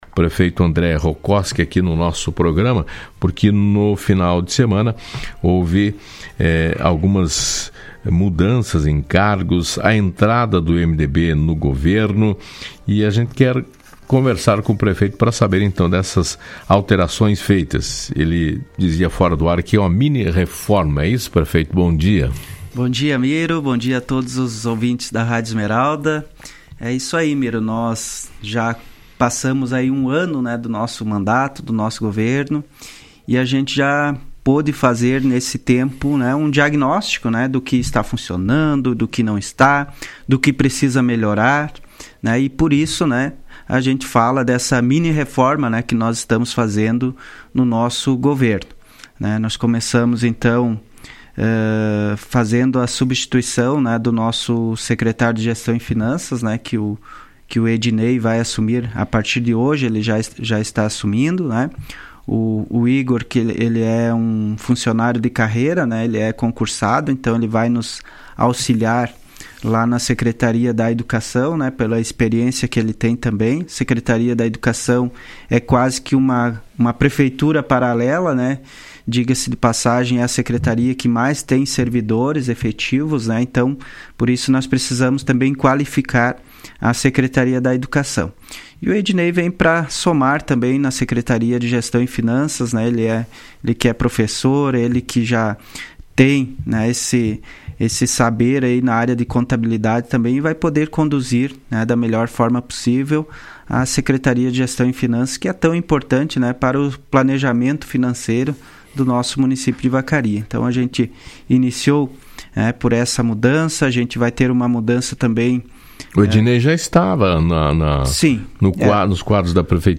Em entrevista ao programa Fala Cidade desta segunda-feira, o prefeito André Rokoski disse que além da entrada do MDB no governo, há uma reformulação em alguns cargos e secretarias.